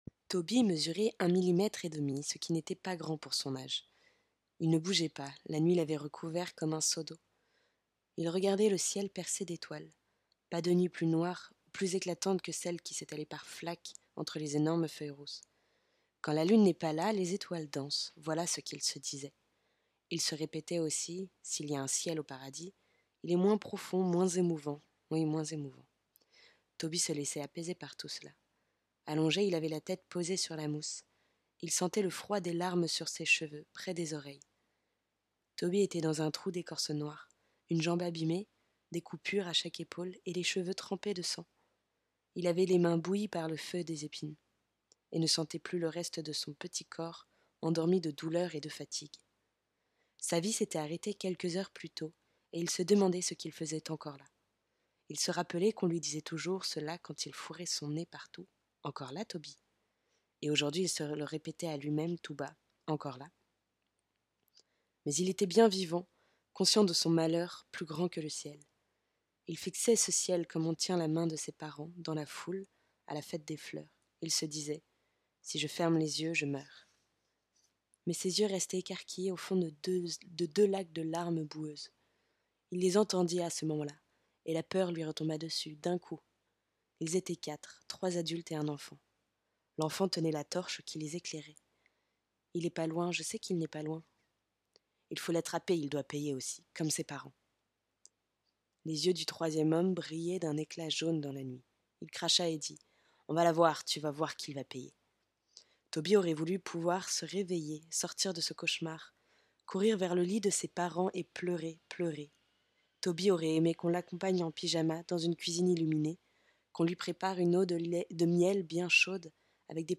Voix off
Lecture livre pour enfant
Contralto